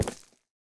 Minecraft Version Minecraft Version 25w18a Latest Release | Latest Snapshot 25w18a / assets / minecraft / sounds / block / deepslate / step5.ogg Compare With Compare With Latest Release | Latest Snapshot
step5.ogg